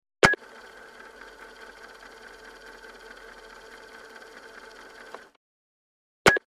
Answering Machine Movement 1; Answering Machine Tape Being Forwarded; Starts With A Short Beep Into A Cassette Being Forwarded And Ending With Another Short Beep. Close Perspective.